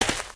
sounds / material / human / step / earth02gr.ogg